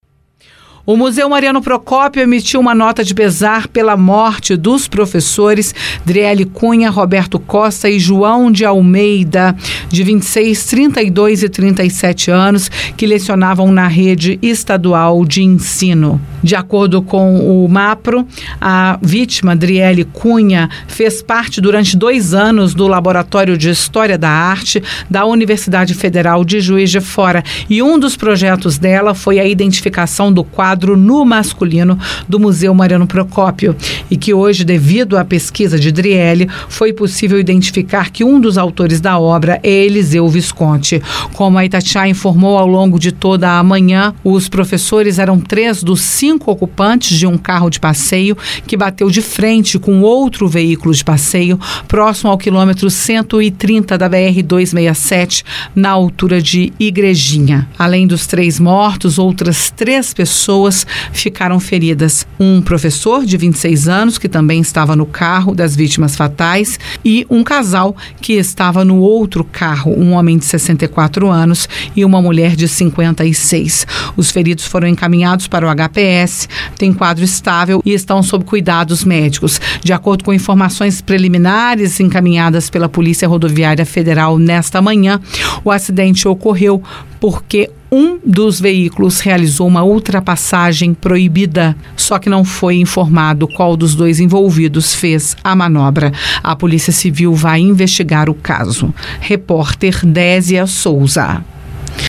Morte-professores-acidente-BR-267-Juiz-de-Fora.mp3